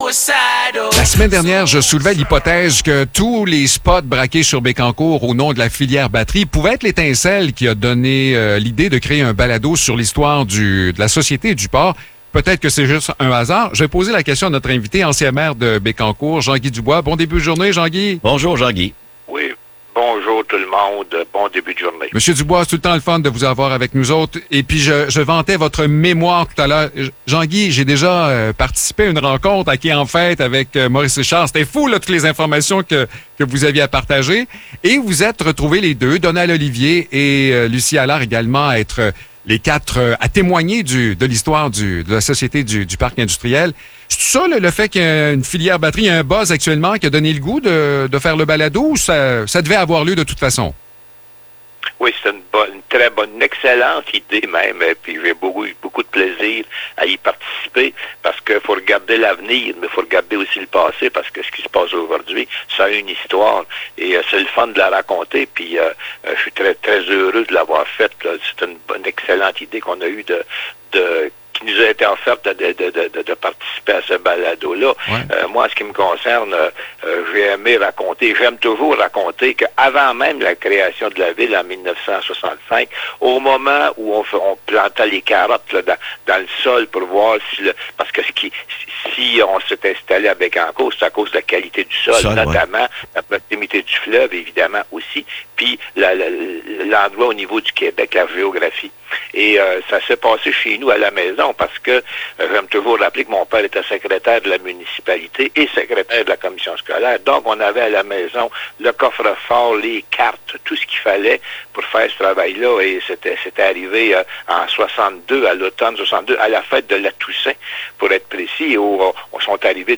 Entrevue avec Jean-Guy Dubois